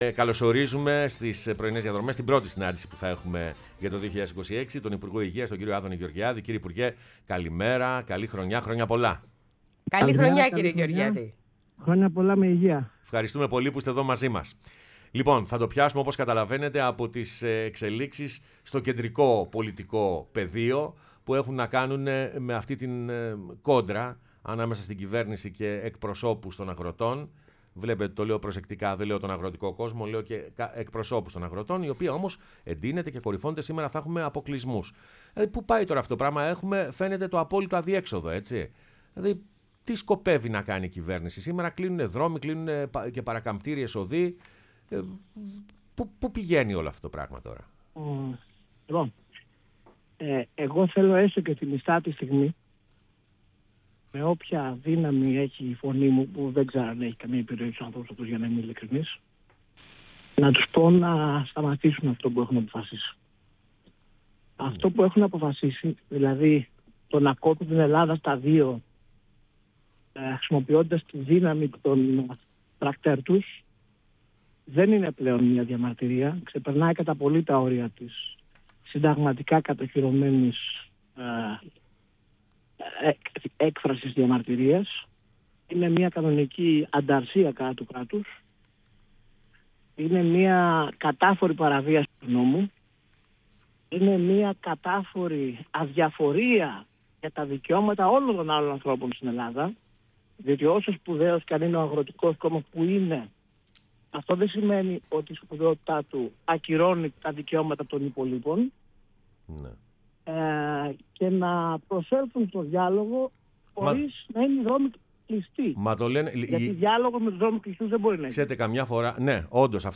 Ο Άδωνις Γεωργιάδης , Υπουργός Υγείας , μίλησε στην εκπομπή Πρωινές Διαδρομές